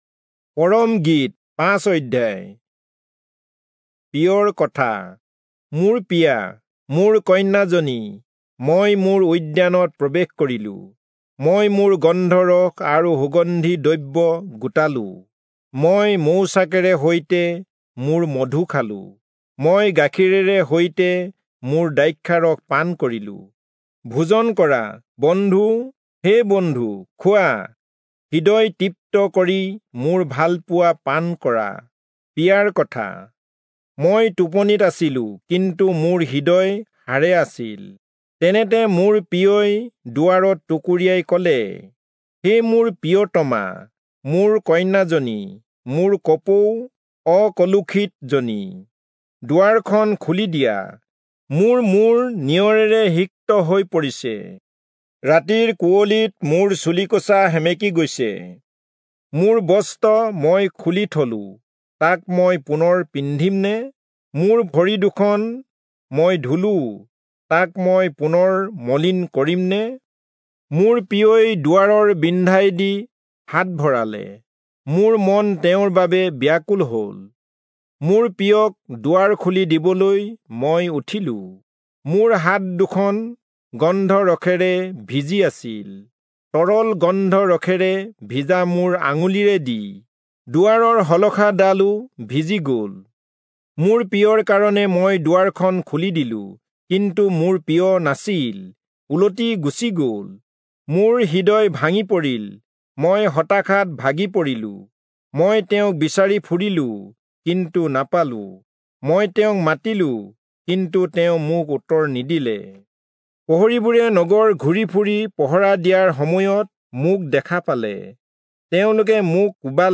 Assamese Audio Bible - Song-of-Solomon 5 in Rv bible version